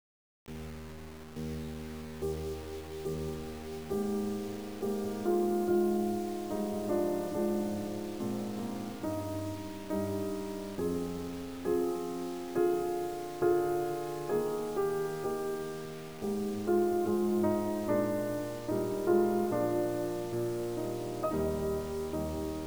To examine the performance of the non-linear compression, I will use a real (piano music) example to showcase the effects of linear/non-linear dynamic compression.
Next follows the naive, linearly compressed dynamic range (8 bit) version. Brace yourself for some unpleasant noise:
bach_kurtag_8bit_stupid.wav